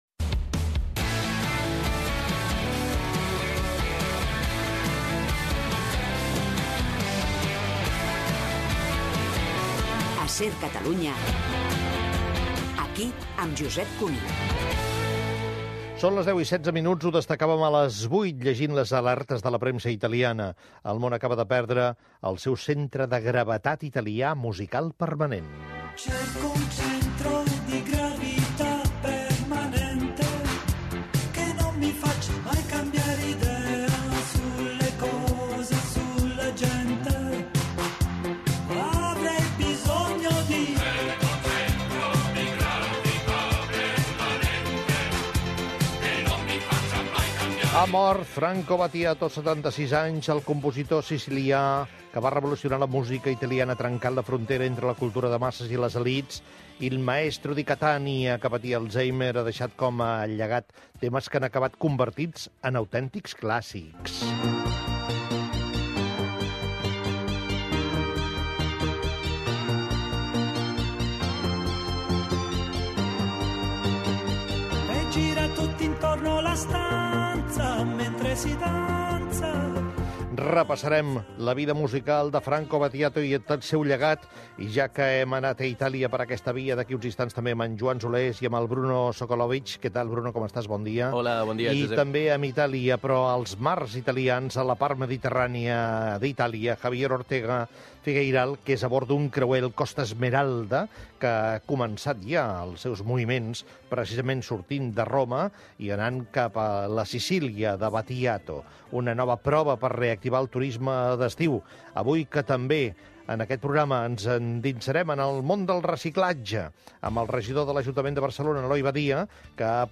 Indicatiu del programa, informació de la mort del cantant Franco Battiato, continguts que tractarà el programa, la pregunta del dia, forma de contactar amb el programa
Gènere radiofònic Info-entreteniment